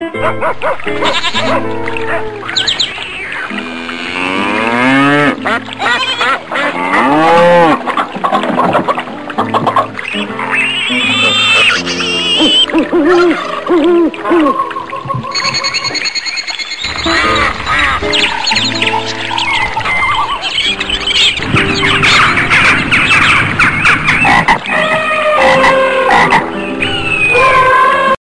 Catégorie Animaux